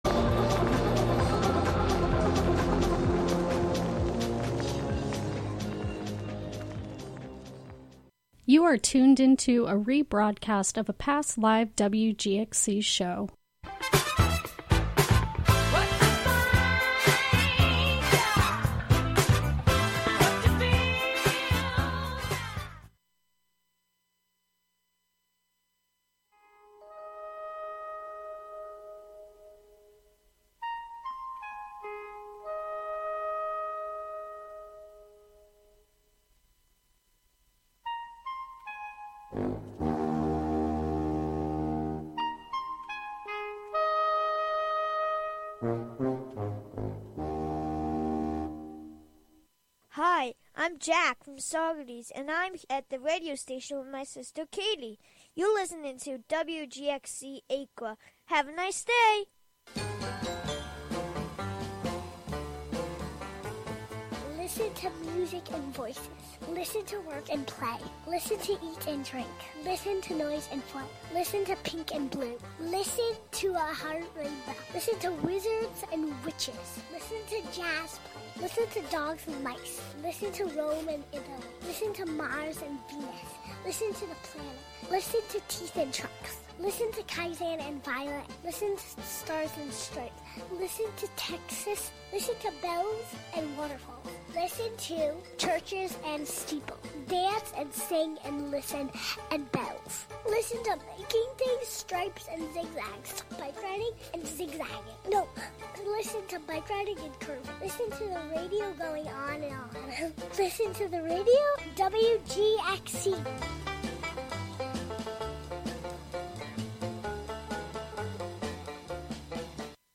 Tune in for special fundraising broadcasts with WGXC Volunteer Programmers!